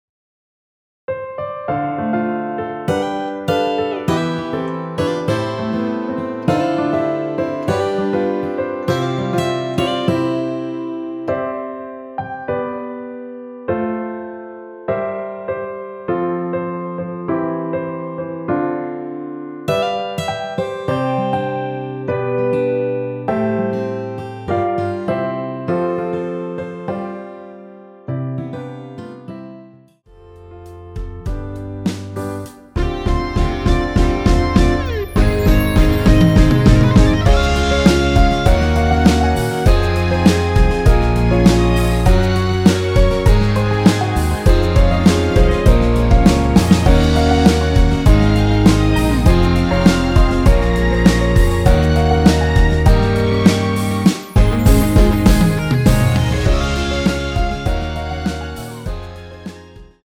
원키에서(+3)올린 MR입니다.
◈ 곡명 옆 (-1)은 반음 내림, (+1)은 반음 올림 입니다.
앞부분30초, 뒷부분30초씩 편집해서 올려 드리고 있습니다.
중간에 음이 끈어지고 다시 나오는 이유는